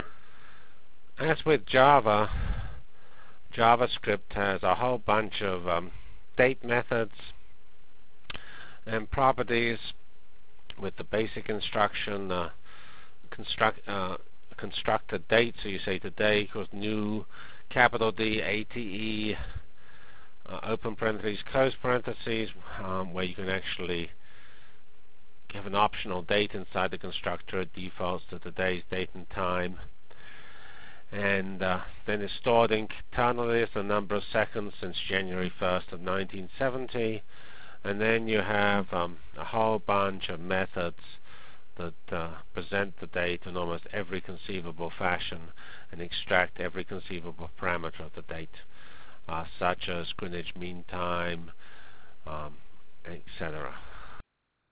Delivered Lecture for Course CPS616